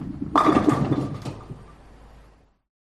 Free SFX sound effect: Metal Clang.
Metal Clang
346_metal_clang.mp3